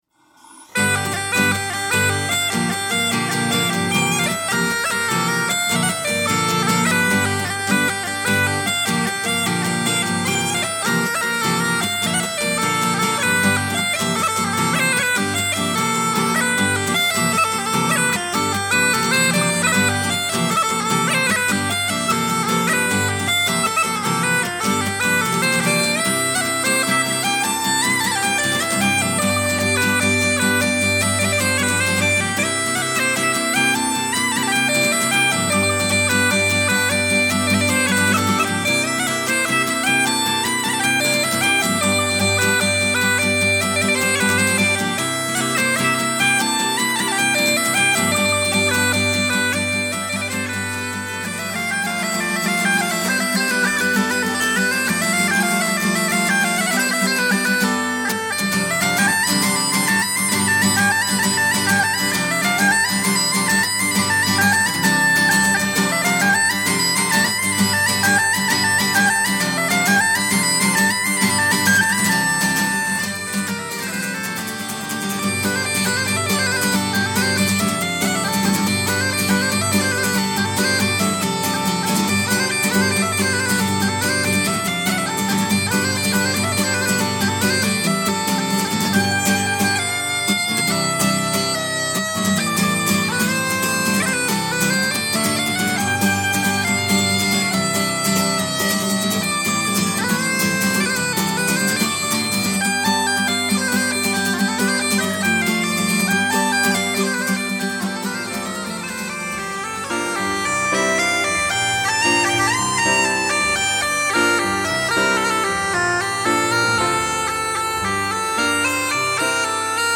New tunes for Border Pipes
border pipes
guitar/piano